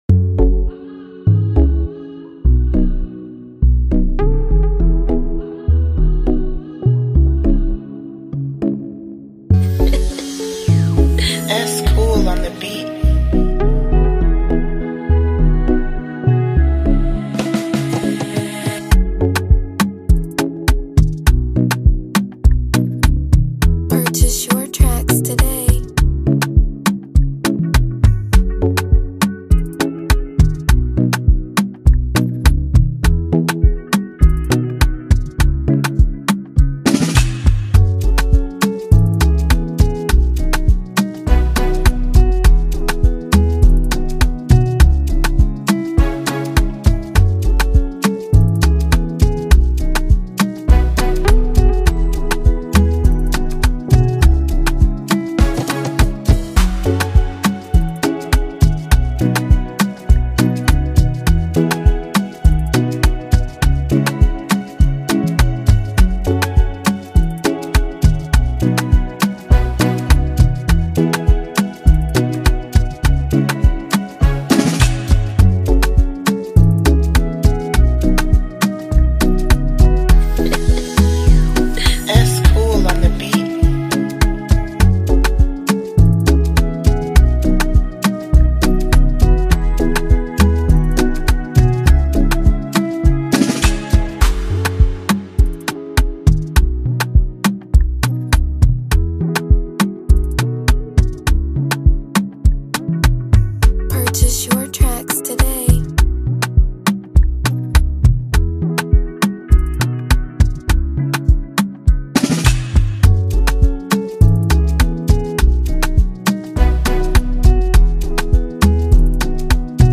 captivating Afrobeat instrumentals
showcases a fusion of cool beats, Nigerian vibes